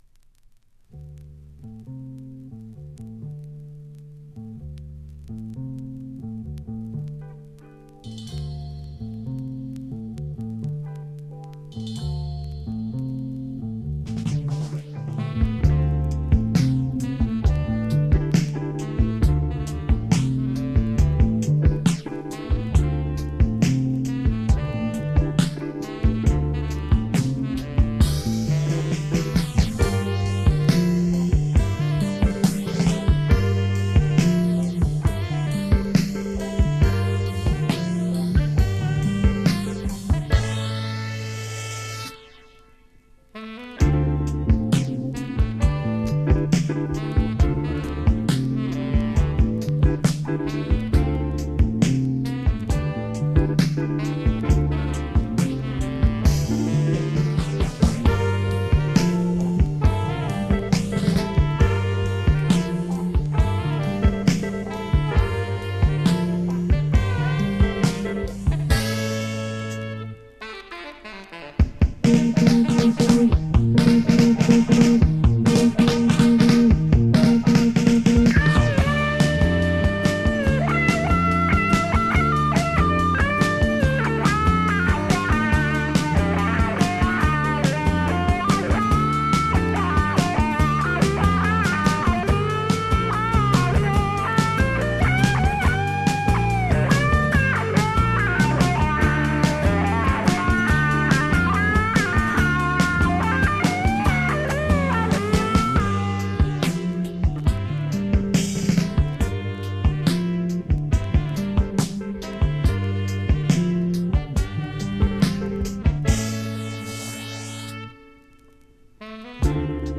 Jazz influenced Progressive rock approach